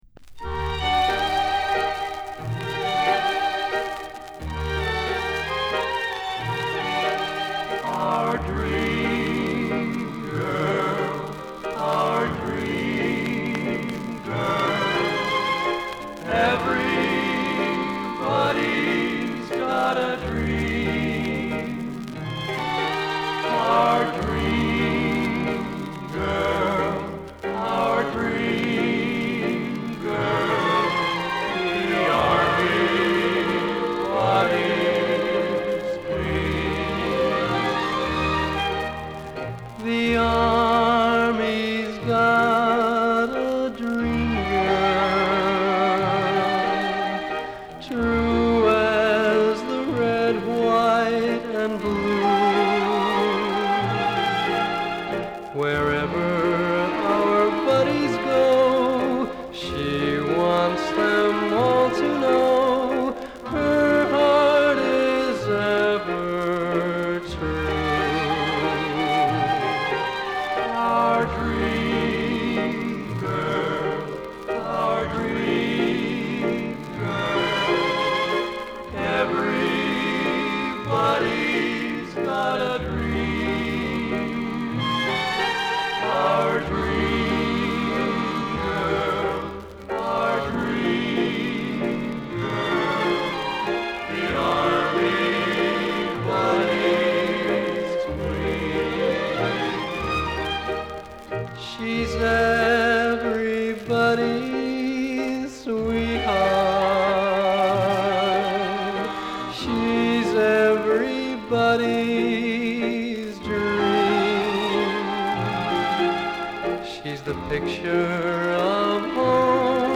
B面は場違いなワルツ・ナンバー。